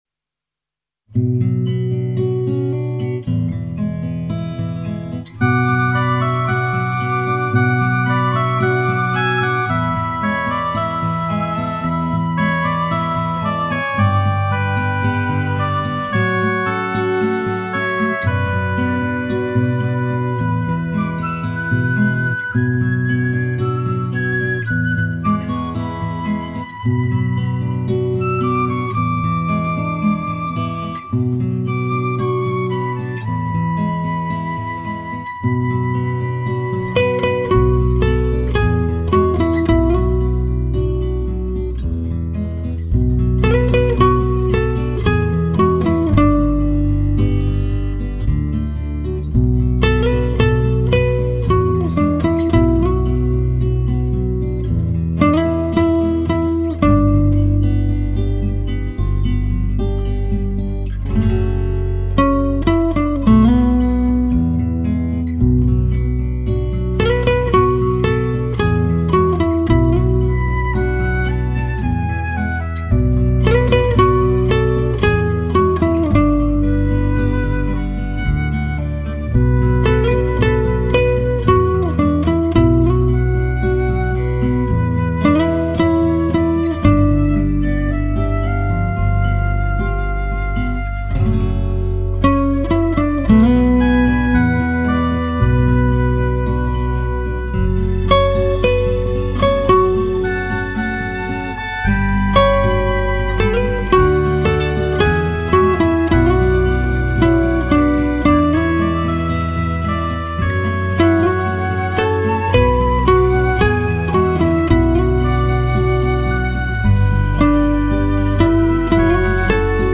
轻轻拔弦，温暖美婉，打动心弦
是一张靓声离奇的吉它音乐示范天碟。